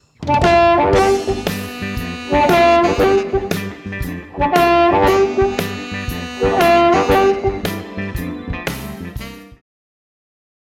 Используя минус, я записал для вас два примера акцентирования.
Фраза, на которой  я это демонстрирую, выглядит следующим образом: -1 -2″ -1 -2 -2